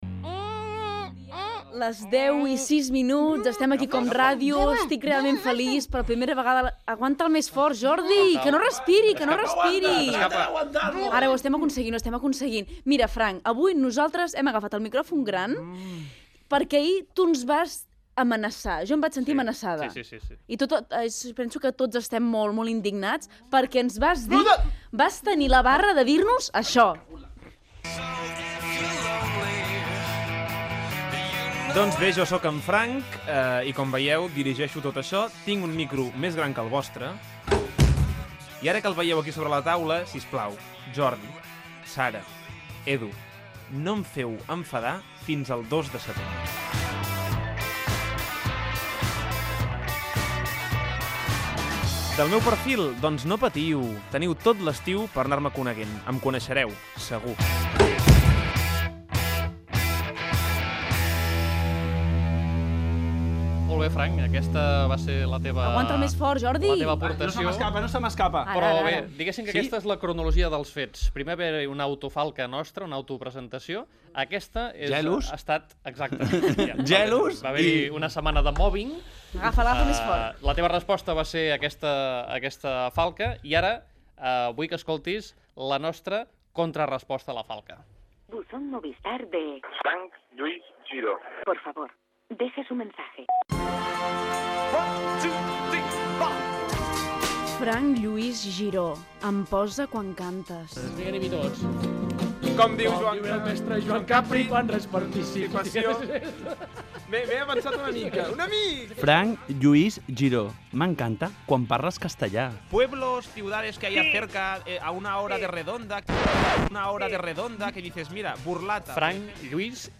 Hora,fragment del programa del dia anterior i comentaris de l'equip del programa sobre possibles promocions del programa.
Entreteniment